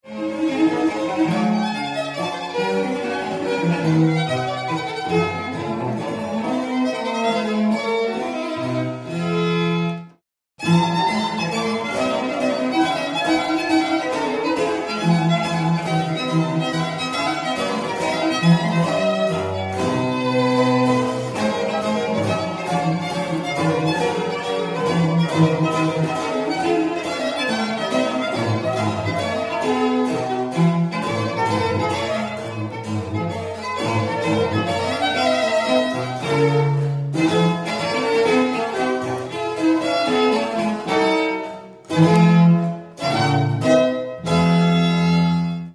1990 first world recording on period instruments